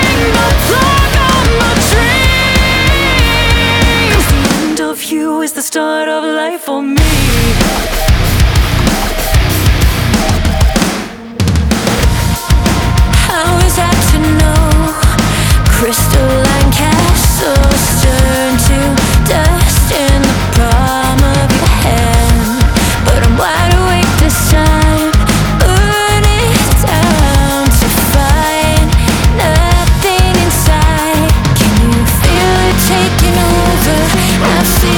Жанр: Рок / Метал